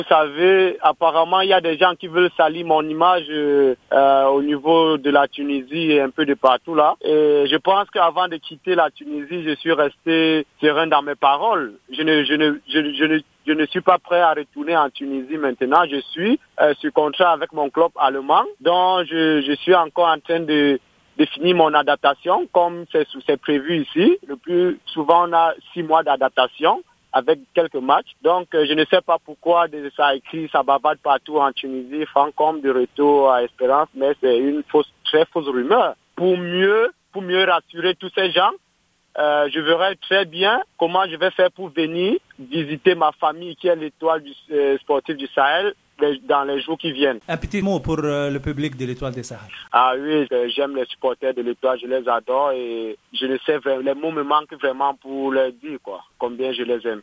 أكد الكامروني و اللاعب السابق للنجم الساحلي و المحترف حاليا في فريق كارلسروه الألماني فرانك كوم في تصريح خاص بجوهرة أف أم مباشرة من ألمانيا أنه لم يتلقى أي عرض للعب في صفوف الترجي الرياضي التونسي كما وقع تداوله مؤخرا .